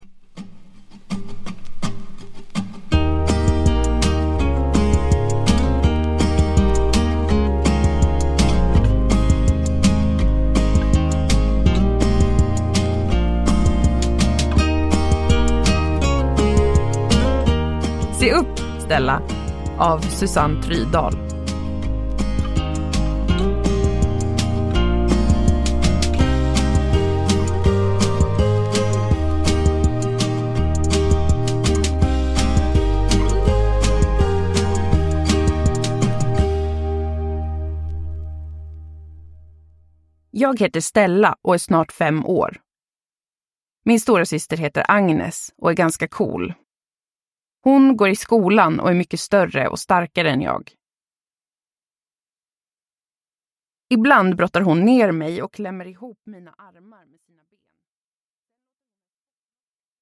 Se upp, Stella! – Ljudbok – Laddas ner